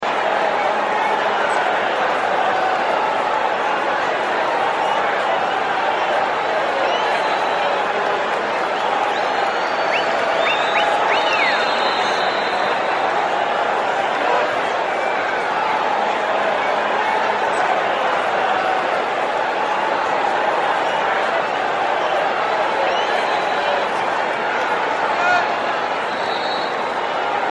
fans_normal_1.wav